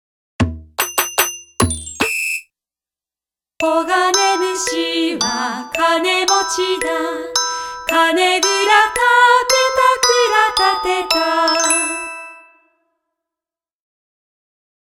童謡を中心にわらべ歌、唱歌、民謡を収録。